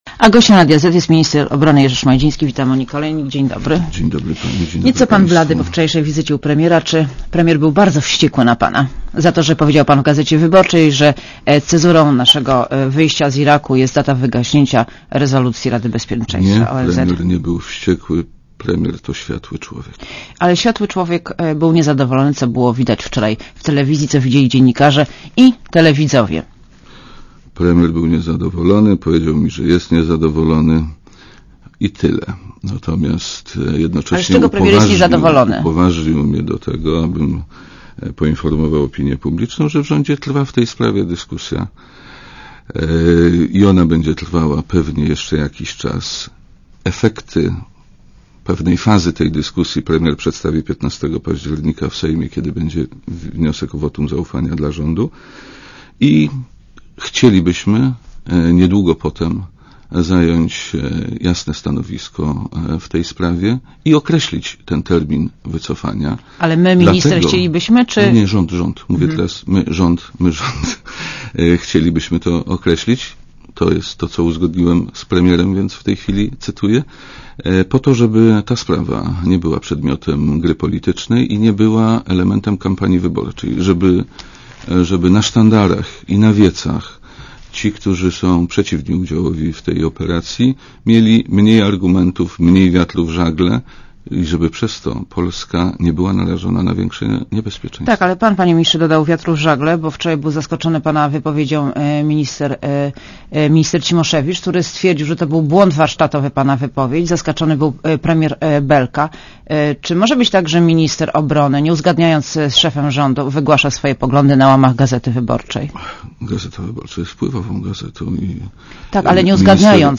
Gościem Radia Zet jest minister obrony, Jerzy Szmajdziński.